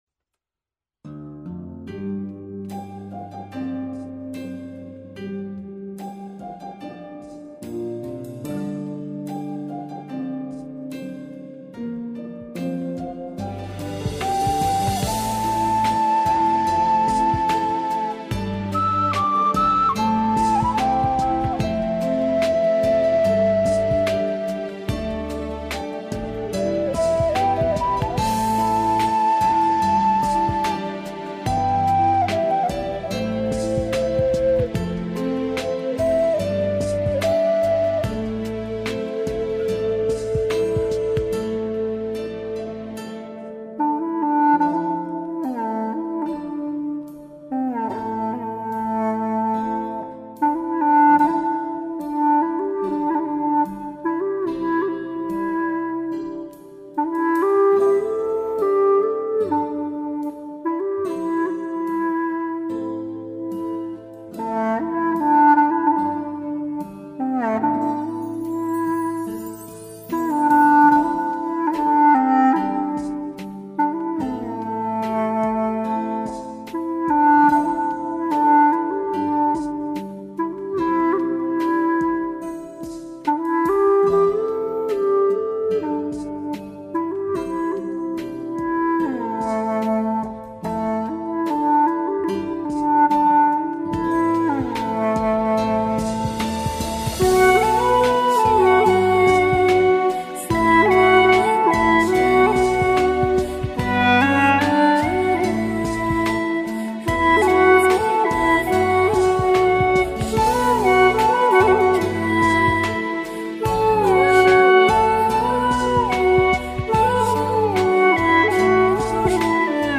调式 : C-D 曲类 : 古风